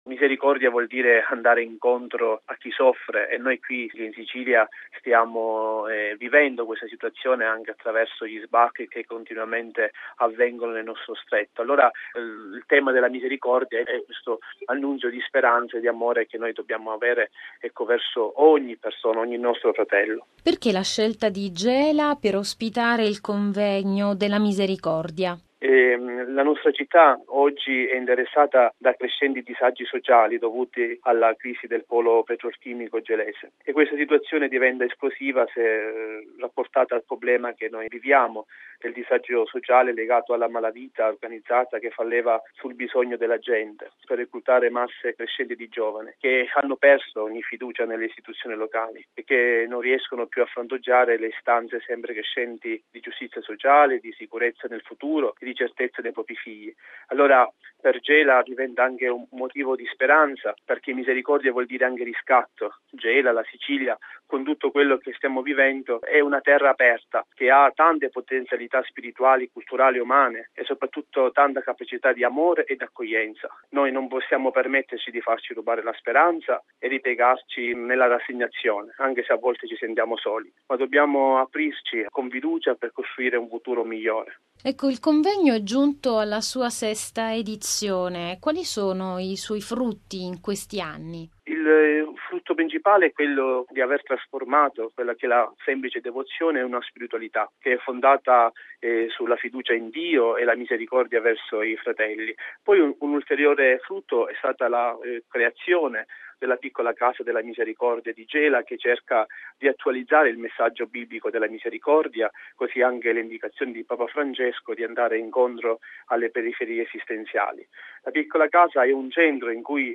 Al microfono